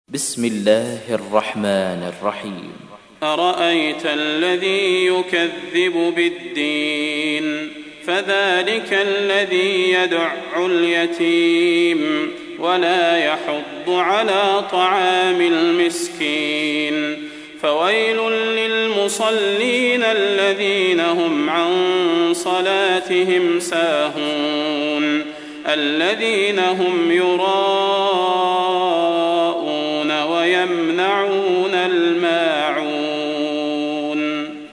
تحميل : 107. سورة الماعون / القارئ صلاح البدير / القرآن الكريم / موقع يا حسين